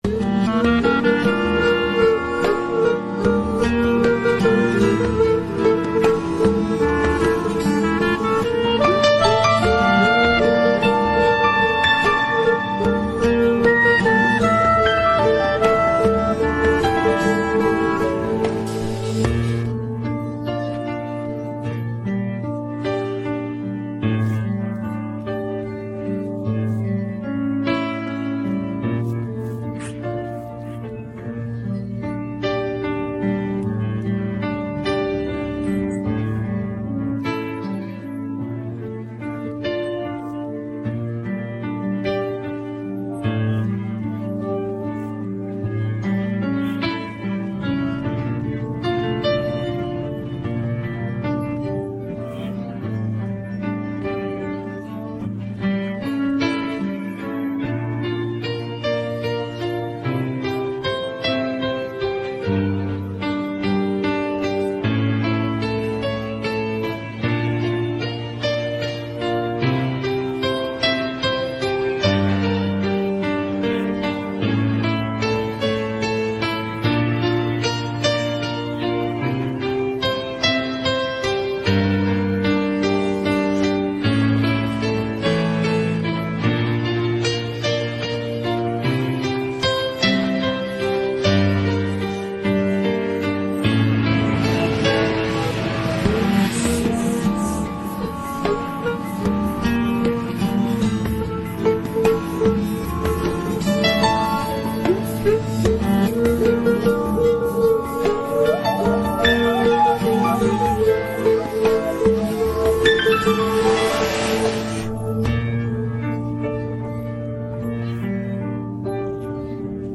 rock караоке 39